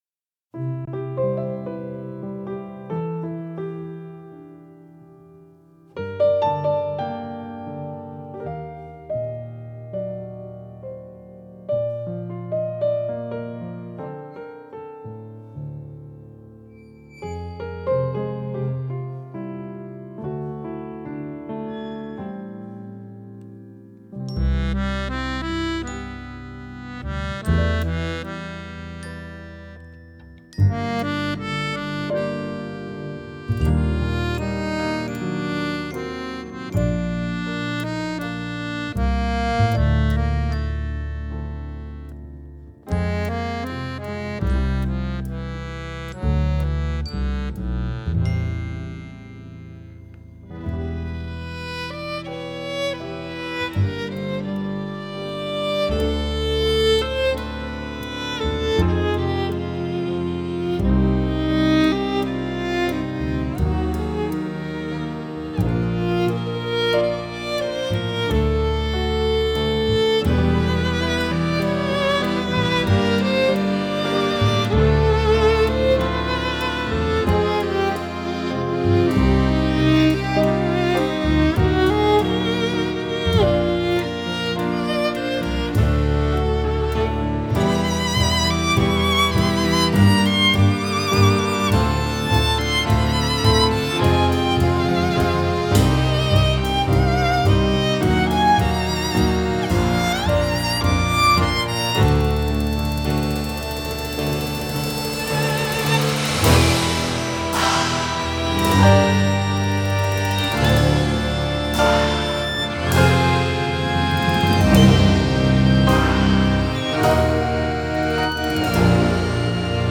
Genre: Jazz, World, Accordion